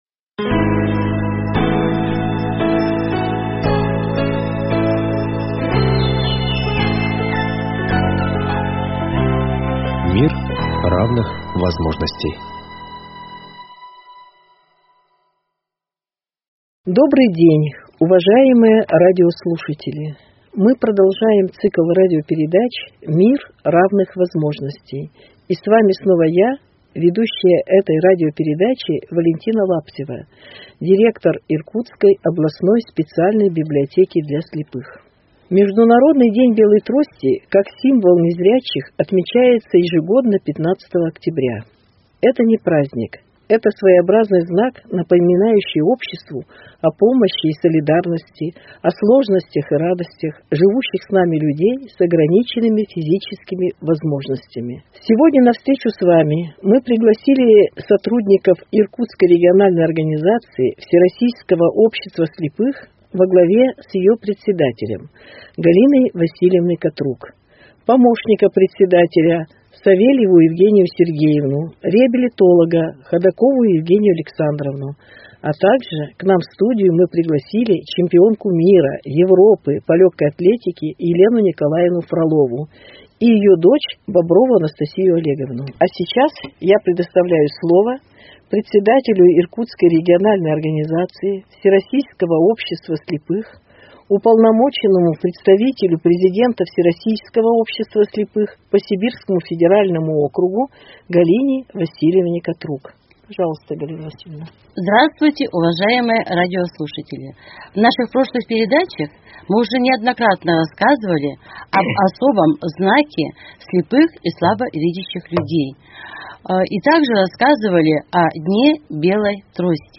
15 октября отмечается «День белой трости». Об этом в студии "Подкаст"а беседуют участники передачи.